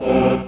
5_choir.wav